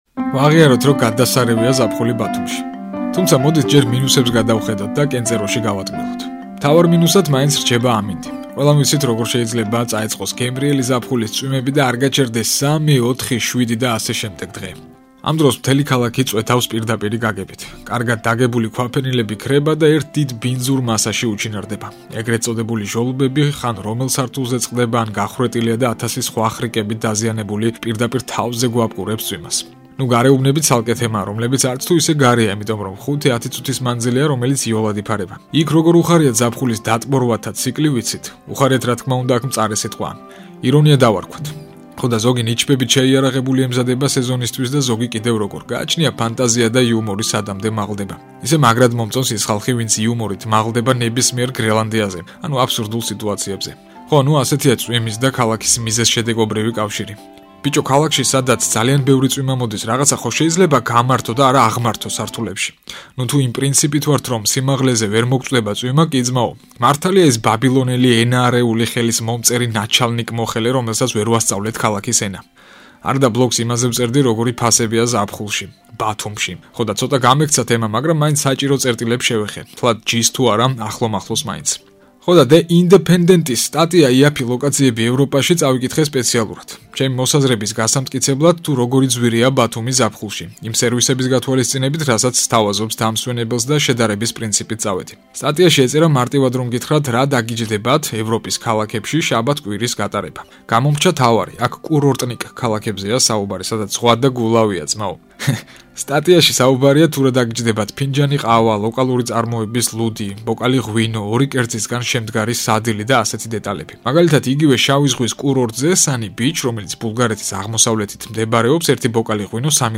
რადიო ბლოგი